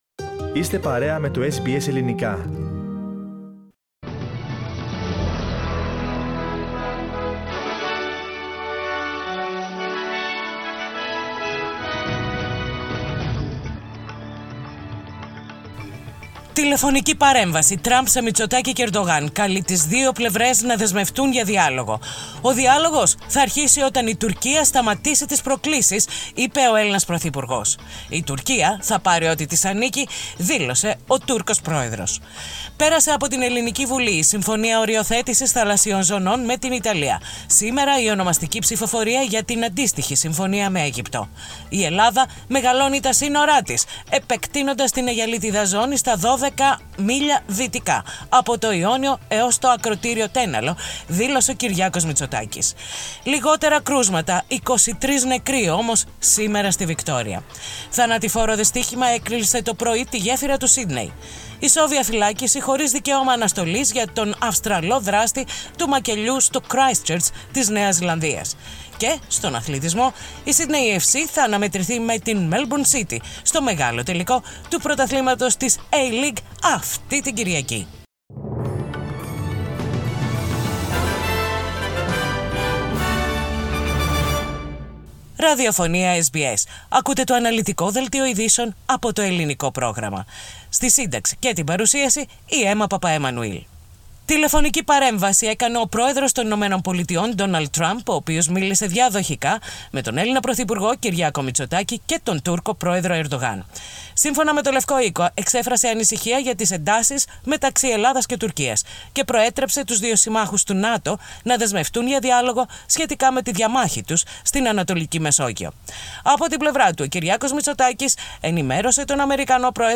Δελτίο ειδήσεων Πέμπτη 27.8.20
Οι κυριότερες ειδήσεις της ημέρας από το Ελληνικό πρόγραμμα της ραδιοφωνίας SBS.